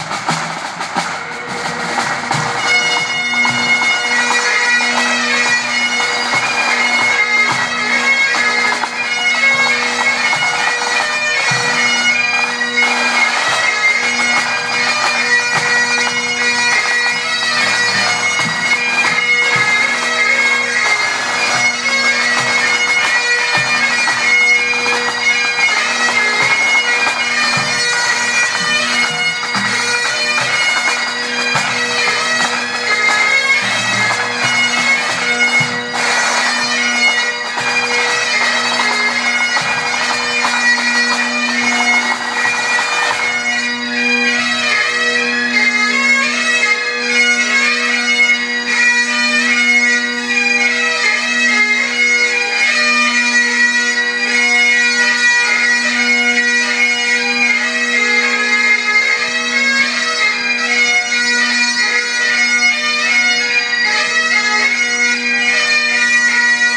Bagpipers practicing outside Belfast Waterfront